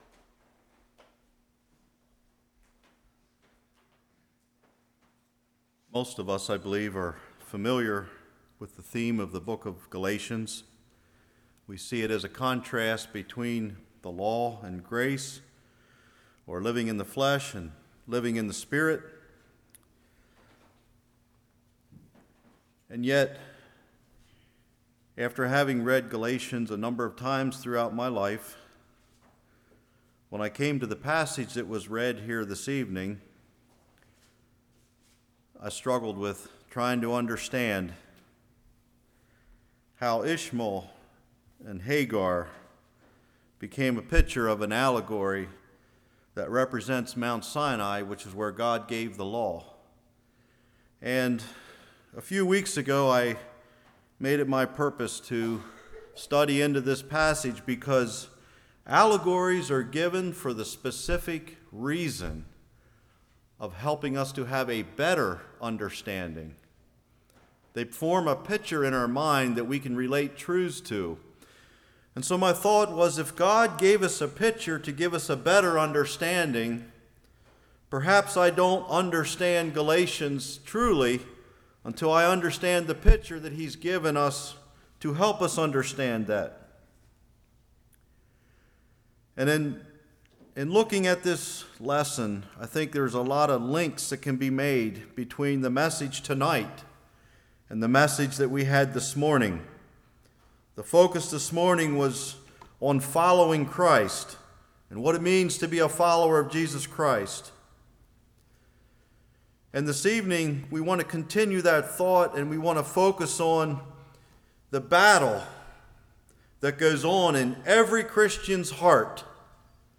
Passage: Galatians 4:21-31 Service Type: Evening Flesh Spirit Promise « This is the Way